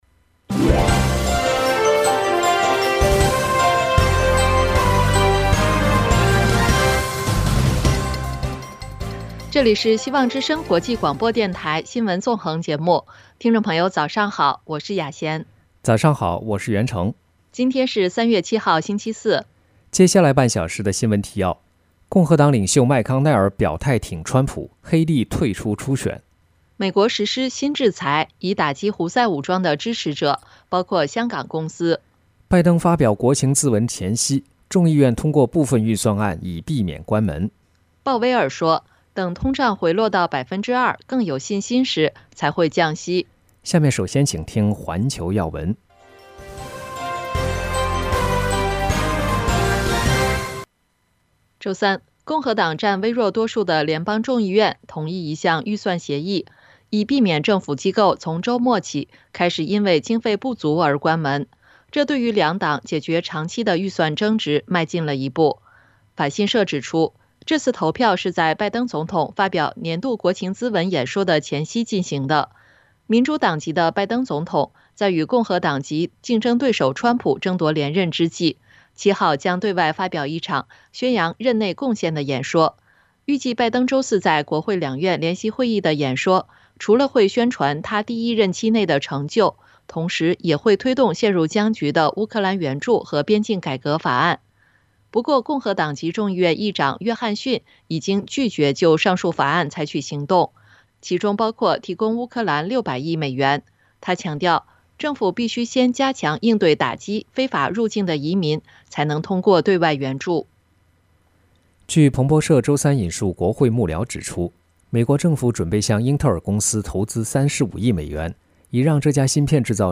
新聞提要（上半場） 環球新聞 1、拜登国情咨文前夕 众议院通过部分预算案以避免关门